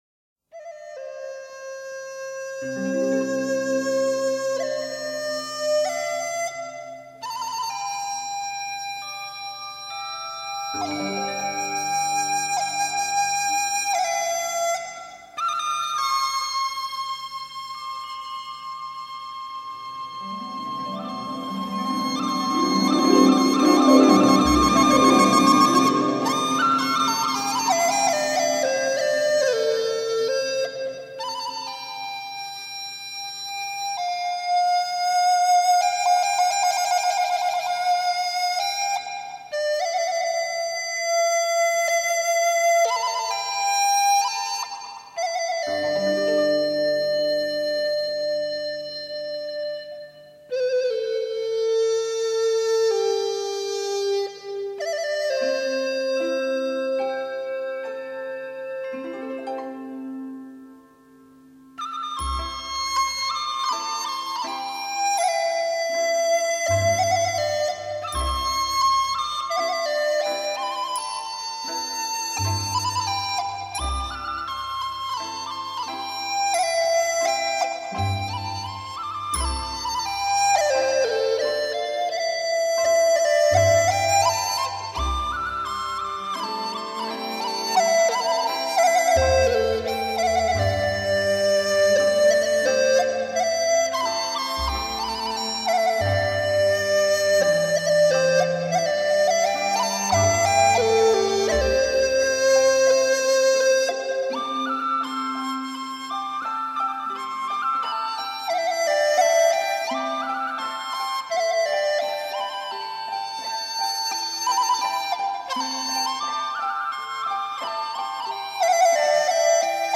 笛子